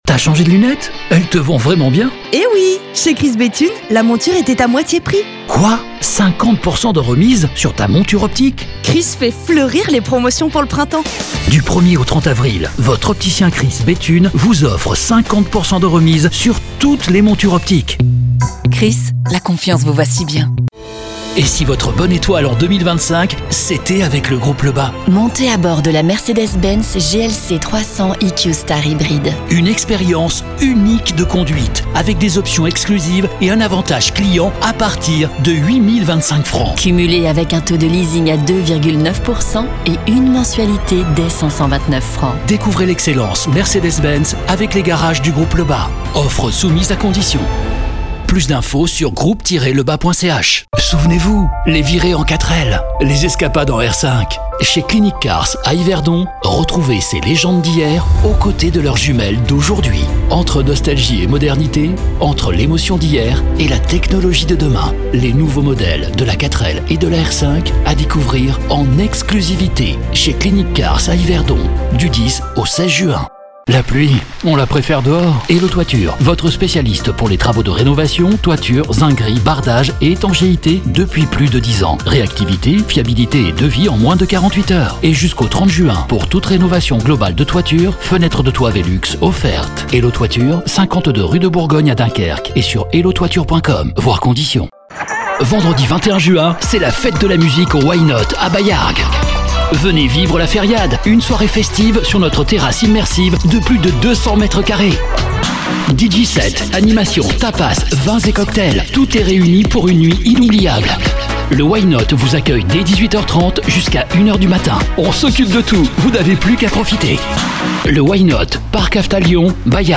Commerciale, Naturelle, Enjouée, Amicale, Mature, Jeune, Cool, Accessible, Polyvalente, Fiable, Corporative, Profonde, Douce
Guide audio